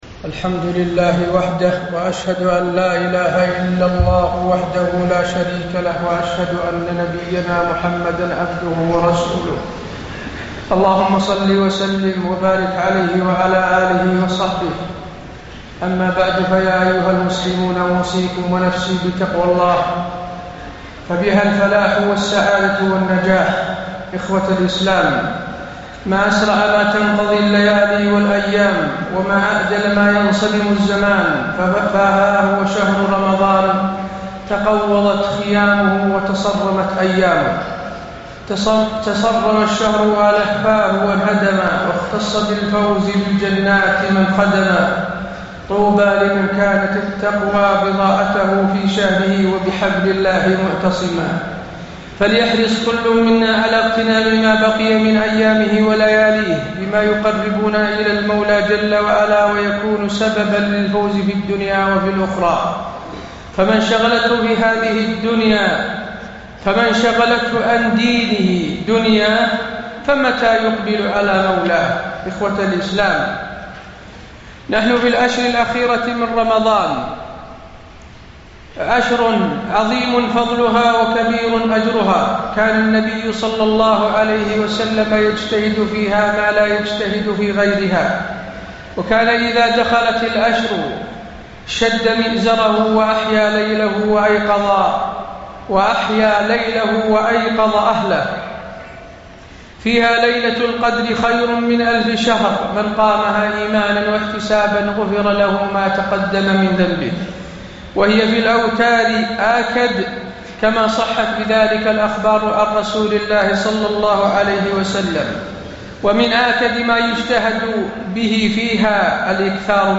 تاريخ النشر ٢١ رمضان ١٤٣٠ هـ المكان: المسجد النبوي الشيخ: فضيلة الشيخ د. حسين بن عبدالعزيز آل الشيخ فضيلة الشيخ د. حسين بن عبدالعزيز آل الشيخ الإعتكاف في العشر الأواخر